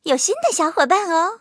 M2中坦建造完成提醒语音.OGG